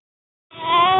baby_cry_detection / crying